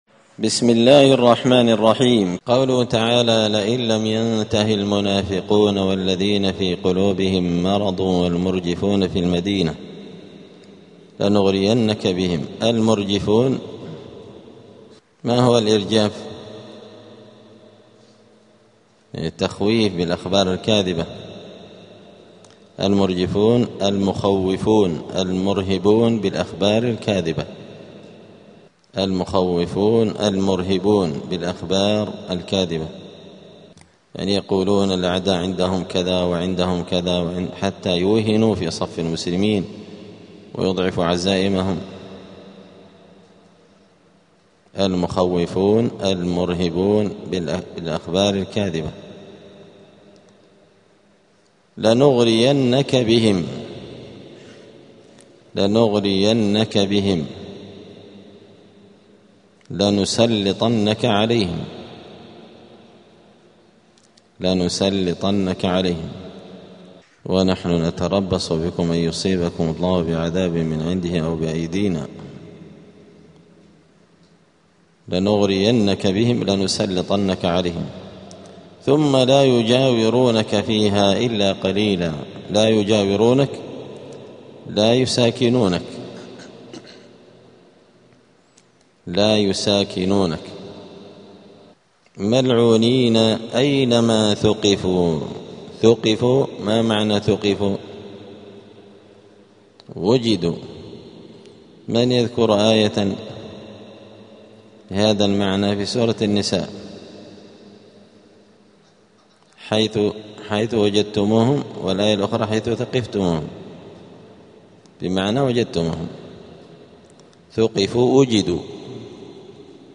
دار الحديث السلفية بمسجد الفرقان قشن المهرة اليمن 📌الدروس اليومية